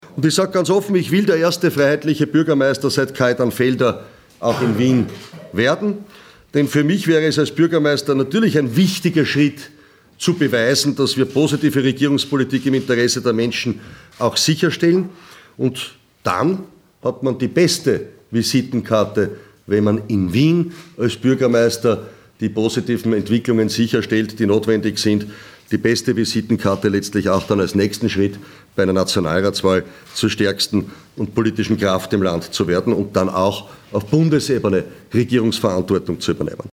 O-Töne von HC Strache